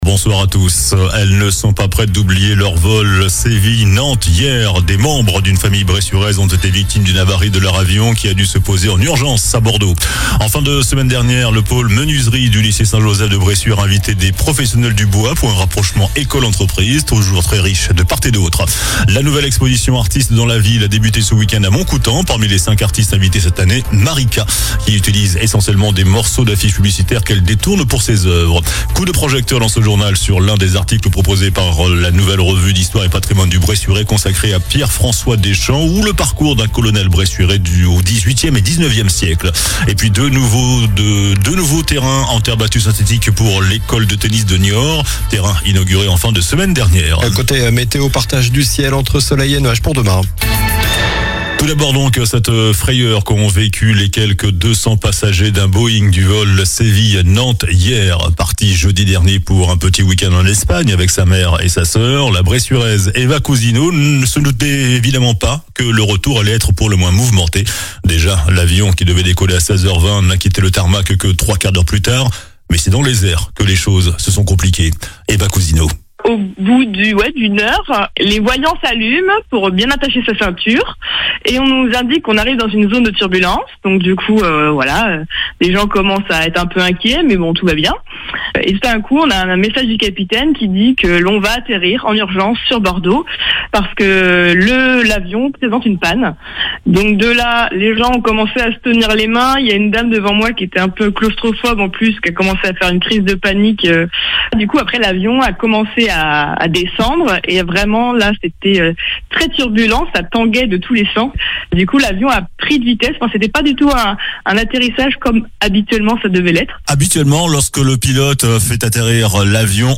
JOURNAL DU LUNDI 24 NOVEMBRE ( SOIR )